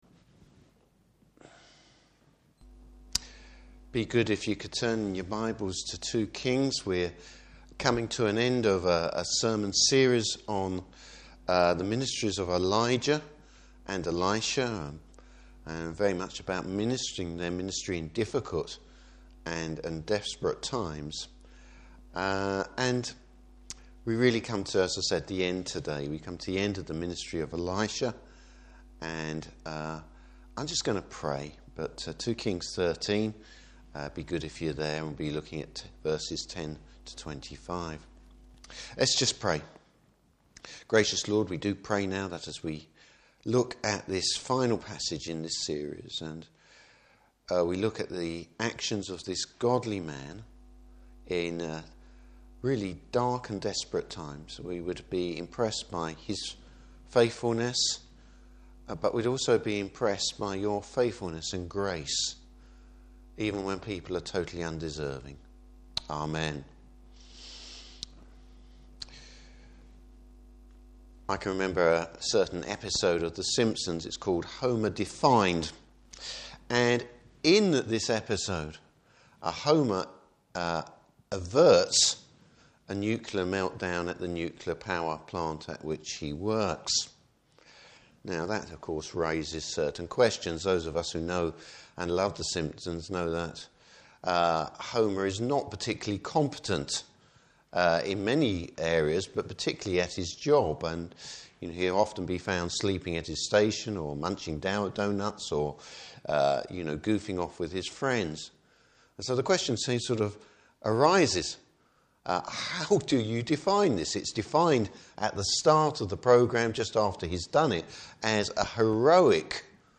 Service Type: Evening Service Bible Text: 2 Kings 13:10-25.